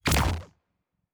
pgs/Assets/Audio/Sci-Fi Sounds/Weapons/Weapon 10 Shoot 3.wav at master
Weapon 10 Shoot 3.wav